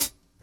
Boom-Bap Hat CL 77.wav